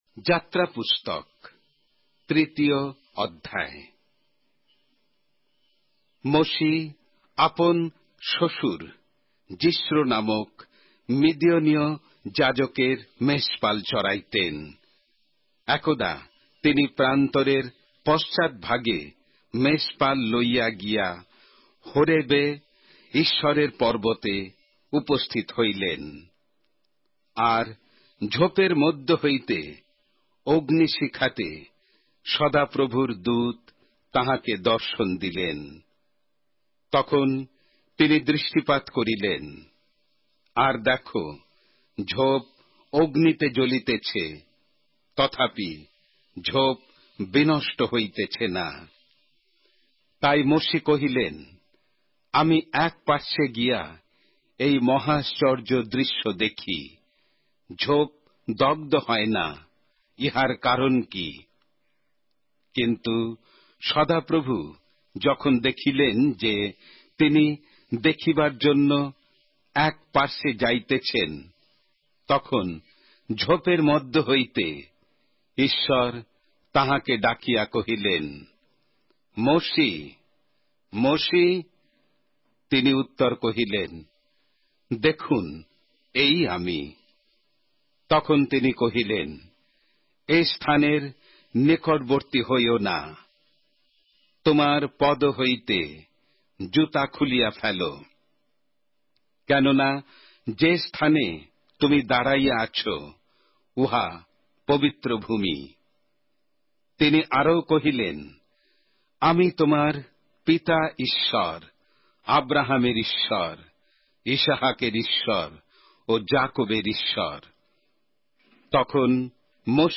Exodus, chapter 3 of the Holy Bible in Bengali:অডিও আখ্যান সঙ্গে বাংলা পবিত্র বাইবেল অধ্যায়,